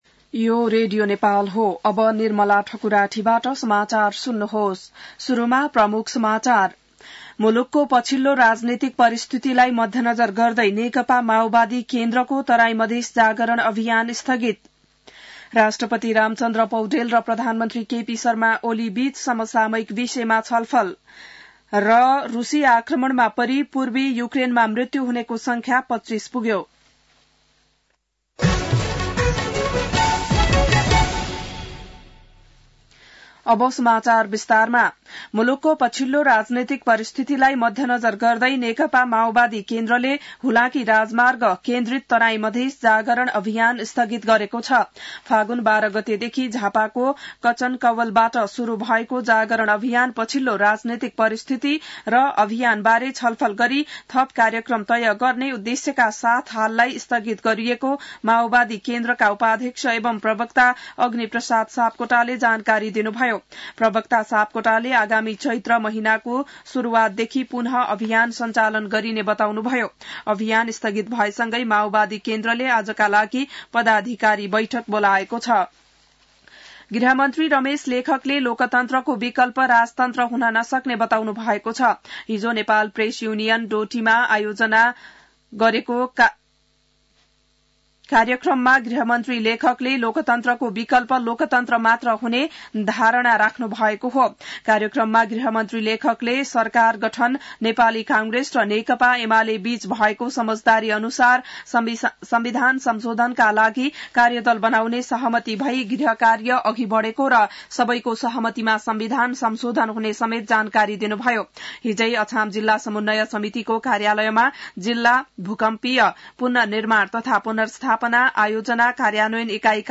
बिहान ९ बजेको नेपाली समाचार : २६ फागुन , २०८१